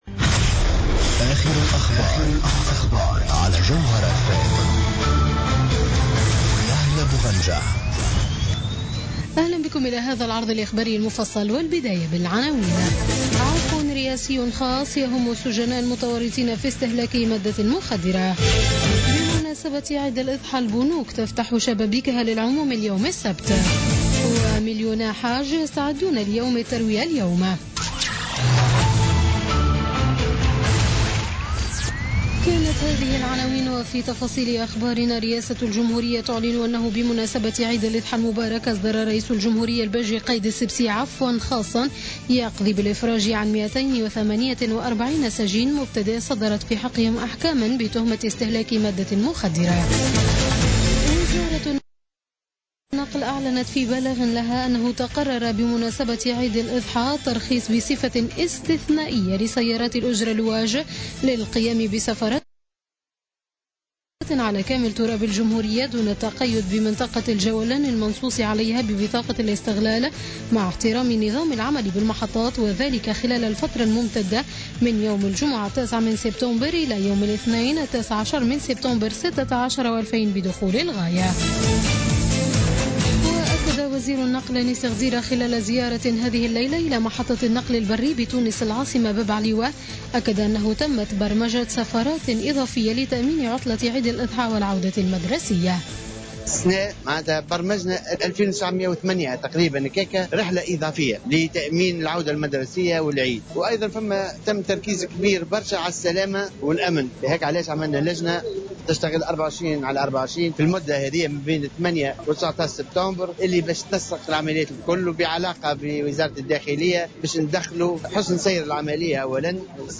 نشرة أخبار منتصف الليل ليوم السبت 10 سبتمبر 2016